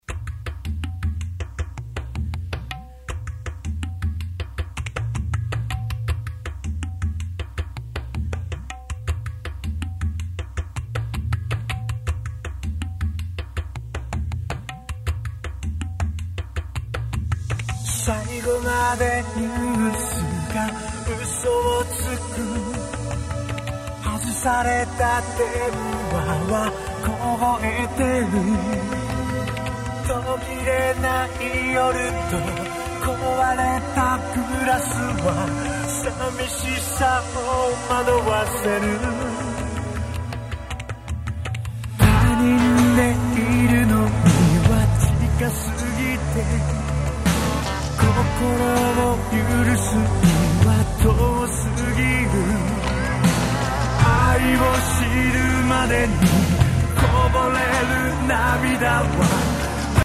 因みにONKYO製の(そこそこまともな)カセットデッキで再生した場合はこんな感じ．